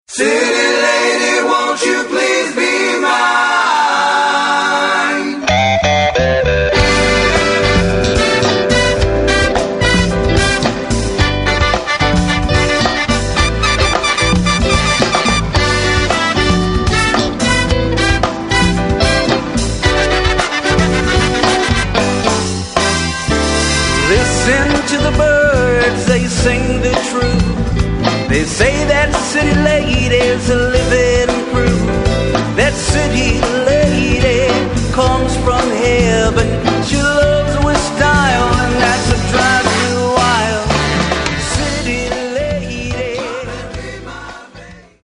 究極のチカーノ・ソウル・コンピレーション！